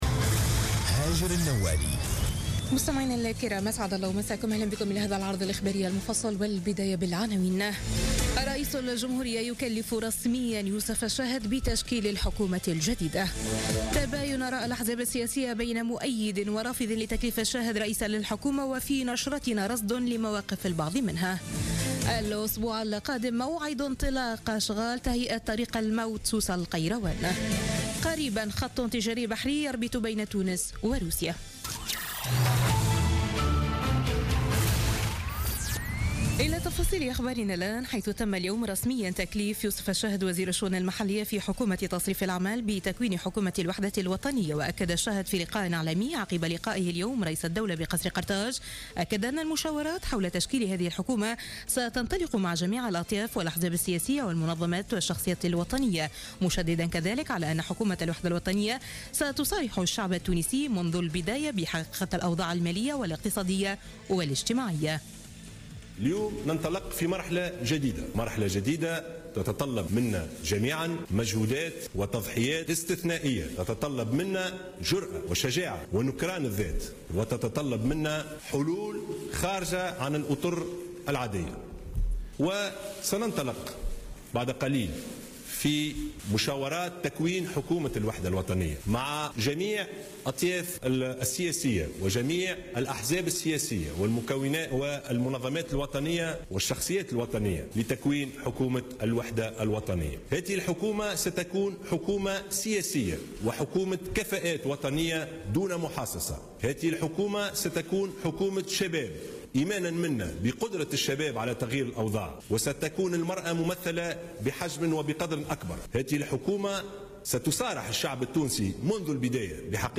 Journal Info 19h00 du mercredi 3 août 2016